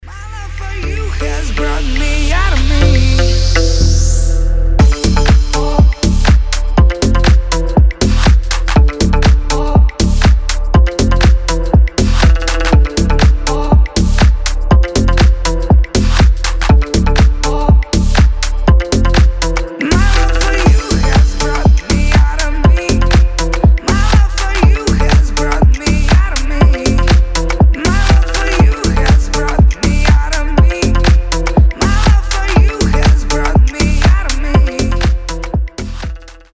• Качество: 320, Stereo
громкие
deep house
Electronic
басы